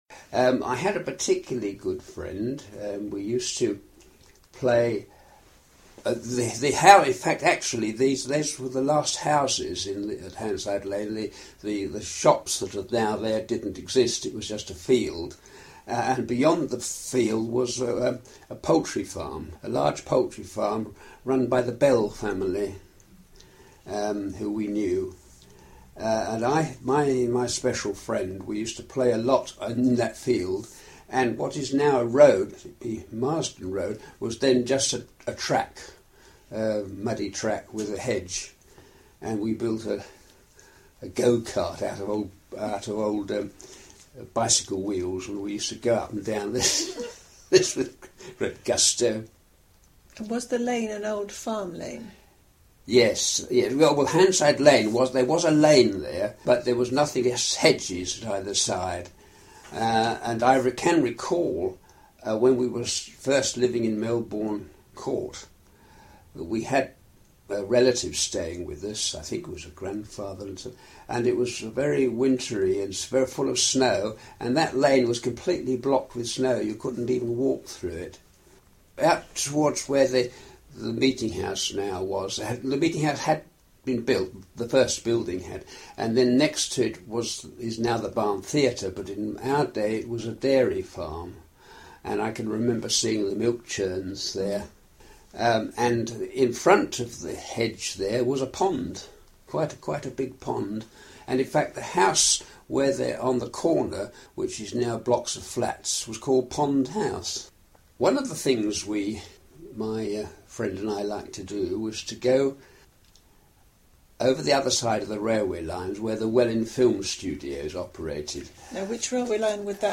Memories - early residents contributed their recollections of the town which were digitally recorded as oral histories by a team of volunteers.